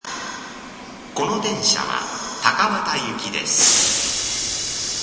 藤が丘方面（女声） 高畑方面（男声）
Hoshigaoka   １番ホーム到着放送
通常タイプの到着放送です。